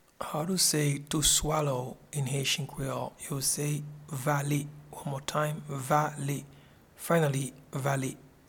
Pronunciation and Transcript:
to-Swallow-in-Haitian-Creole-Vale.mp3